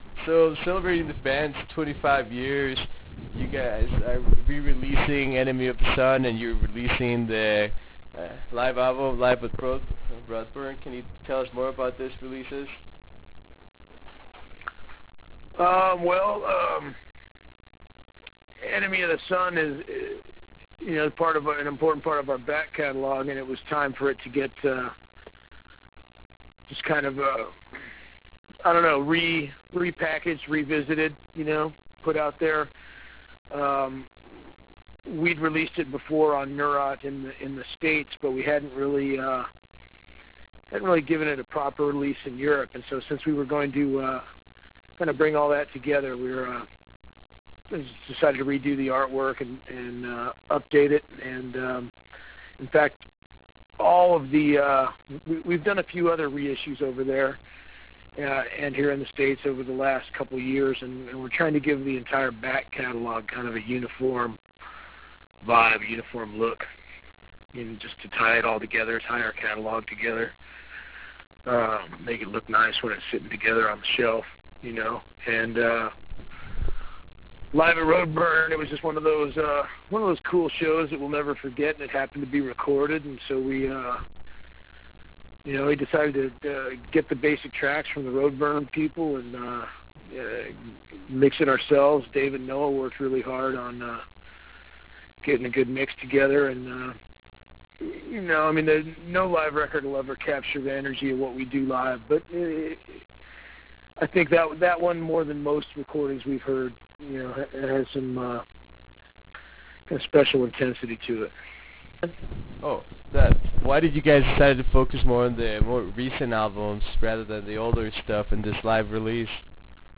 Interview with Steve Von Till - Neurosis.wav